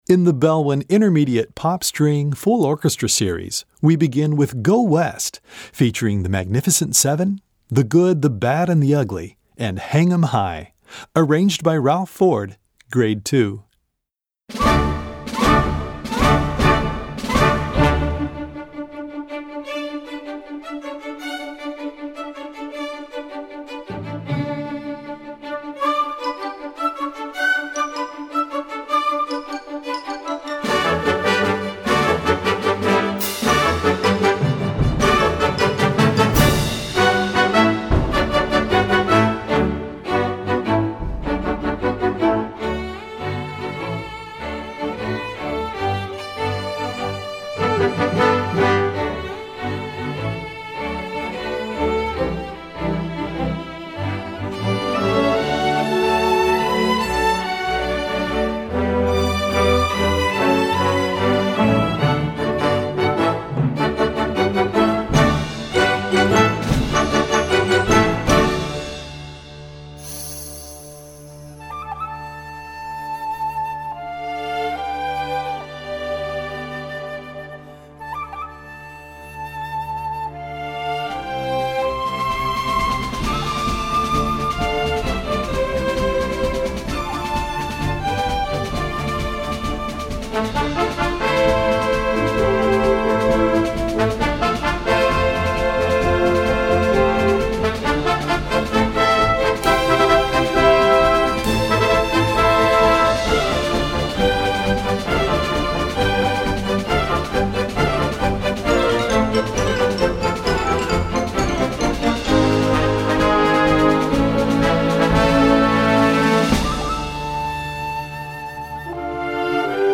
Gattung: Sinfonieorchester - Medley
Besetzung: Sinfonieorchester